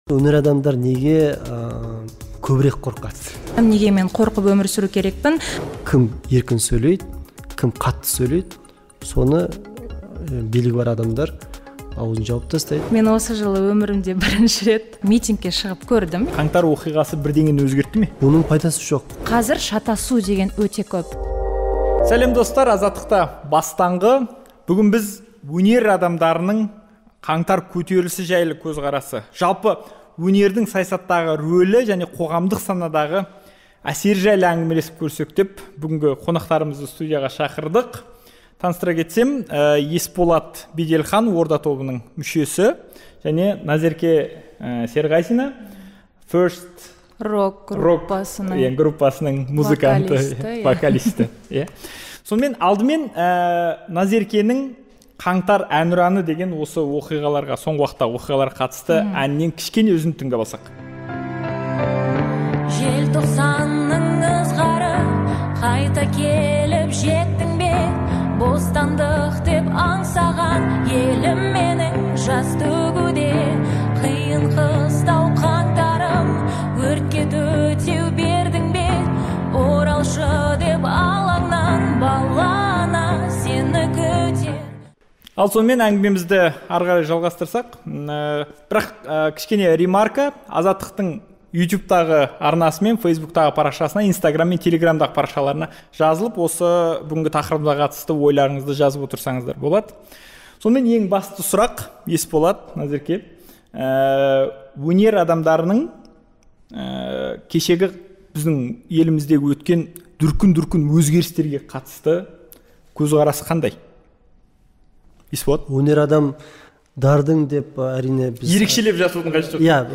"Бастаңғыда" өнер адамдары саясат жайлы пікір айтудан қорқа ма деген тақырыпты талқыладық. Студия қонақтары - әншілер